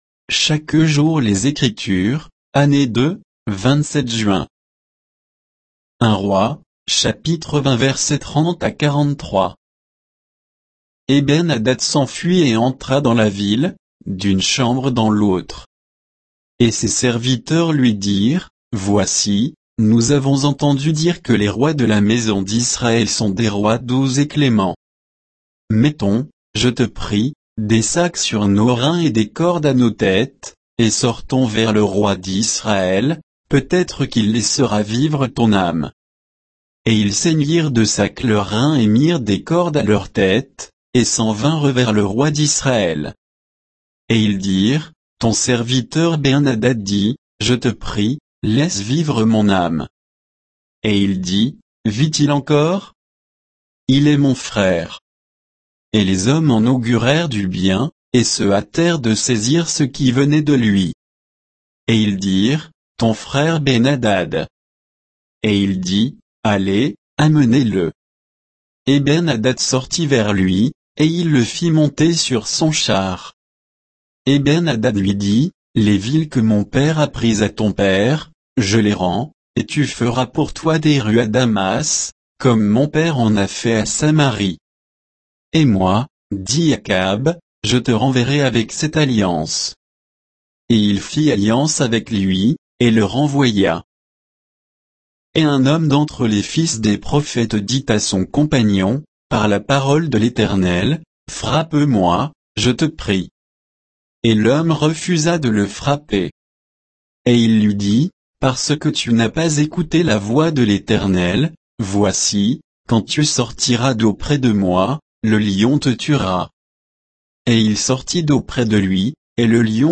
Méditation quoditienne de Chaque jour les Écritures sur 1 Rois 20